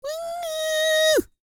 E-CROON 3044.wav